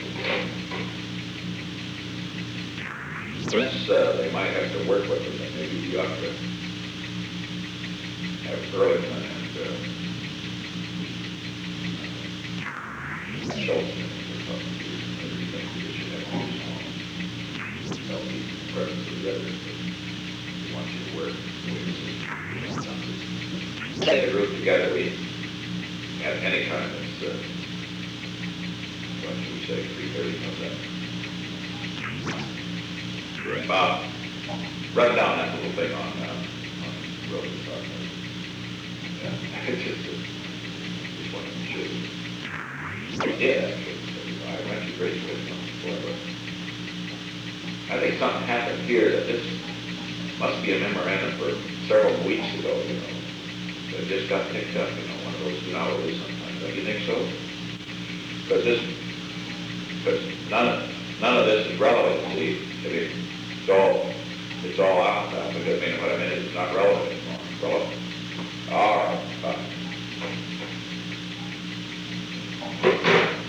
The Old Executive Office Building taping system captured this recording, which is known as Conversation 295-008 of the White House Tapes.